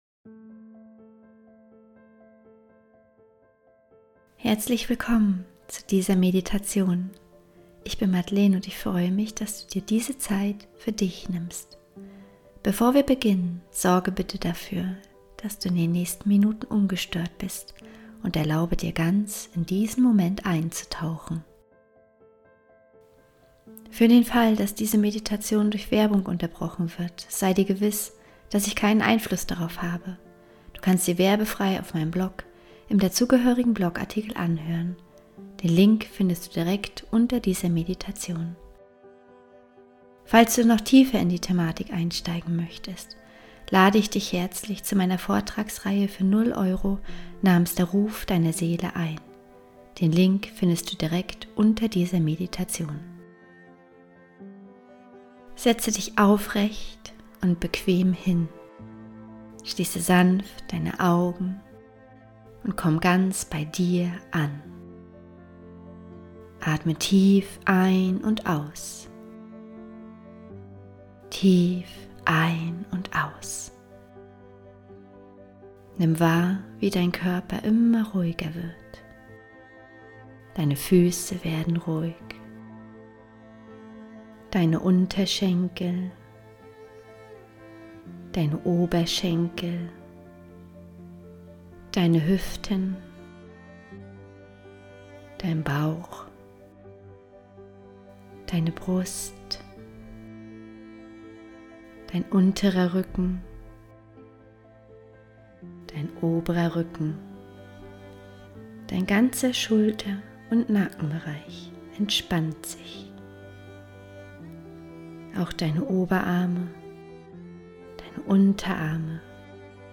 15 Minuten geführte Meditation: Von fremden Erwartungen zur inneren Freiheit ~ Heimwärts - Meditationen vom Funktionieren zum Leben Podcast
15_Minuten_gefuehrte_Meditation_von_Erwartungen_anderern_zur_inneren_Freiheit.mp3